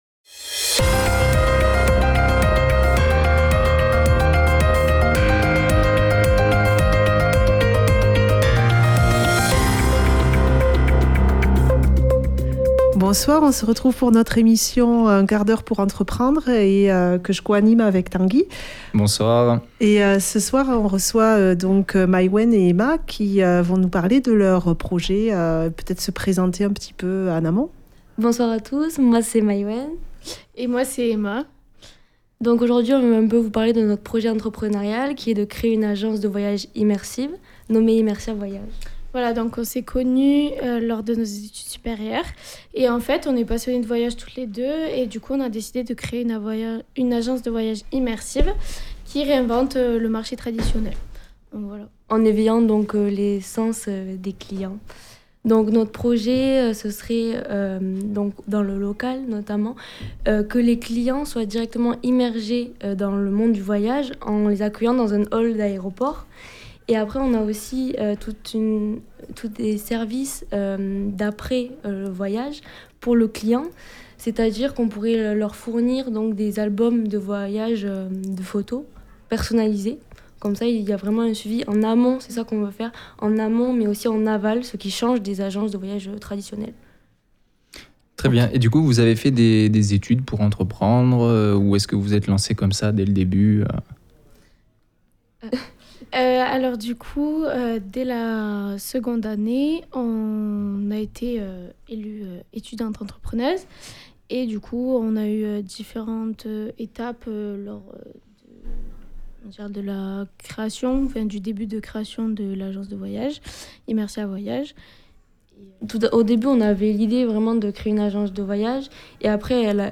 Reportages